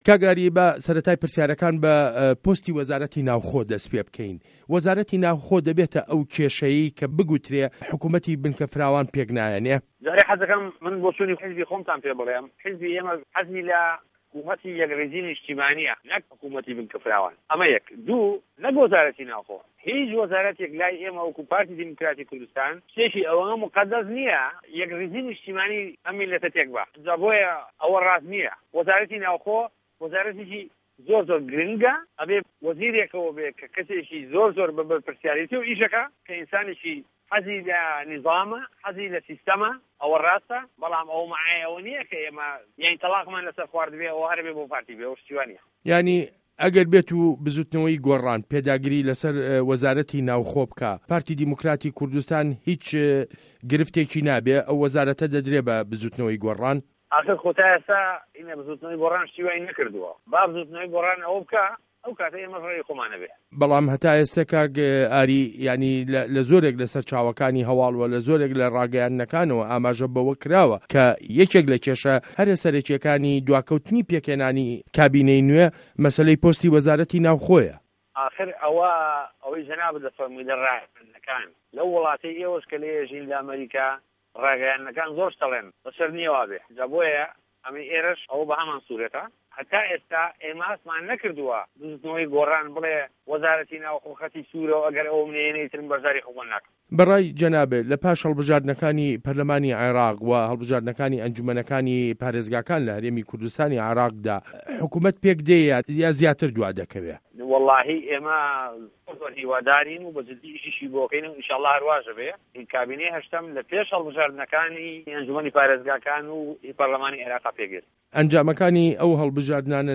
وتووێژ له‌گه‌ڵ ئاری هه‌رسین